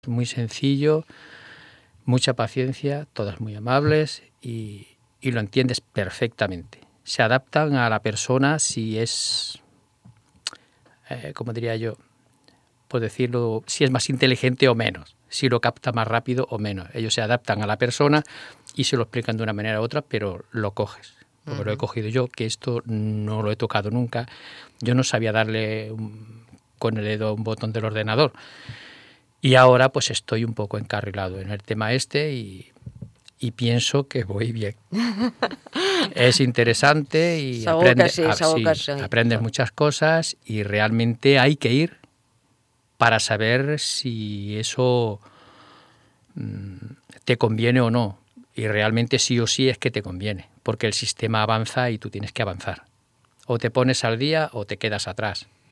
Aquesta setmana hem anat a Ràdio Mollet a explicar les càpsules que oferim als ciutadans i volem compartir amb vosaltres diferents talls de veu que demostren que estem assolint els objectius amb els usuaris que hi assisteixen: